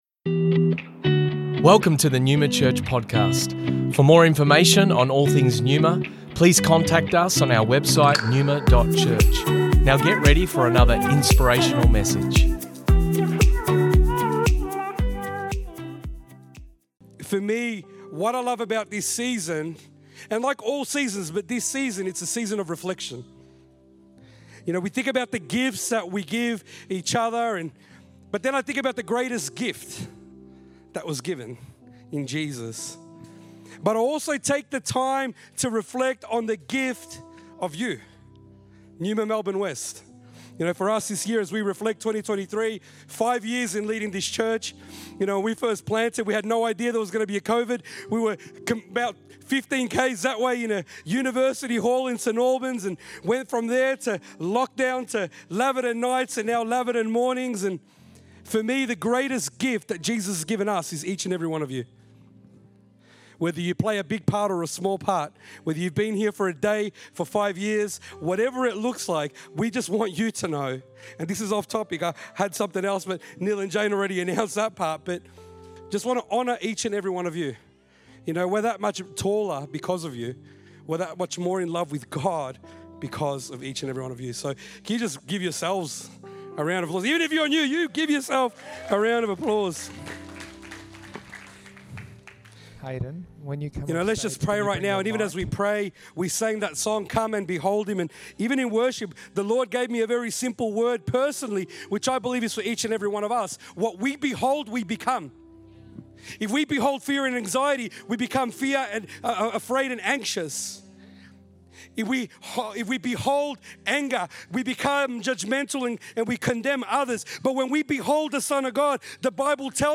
Originally recorded at Neuma Melbourne West December 24th 2023